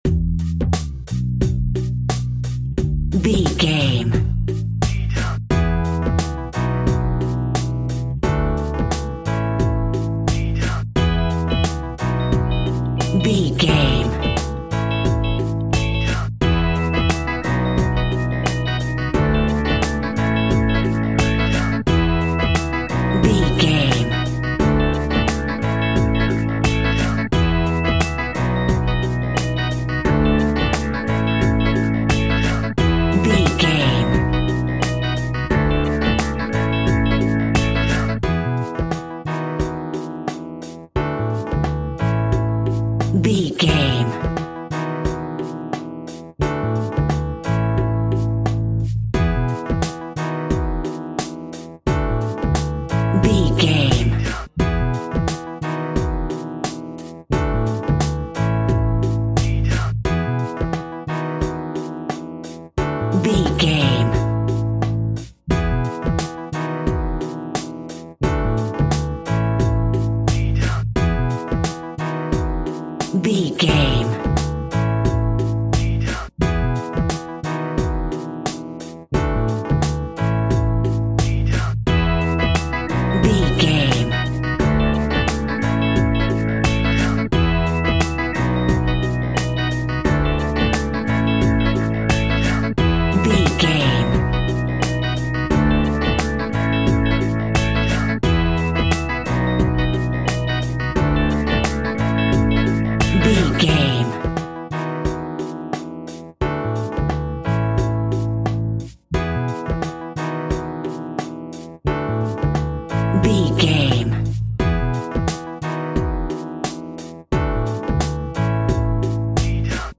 Ionian/Major
Funk
hip hop
electronic
drum machine
synths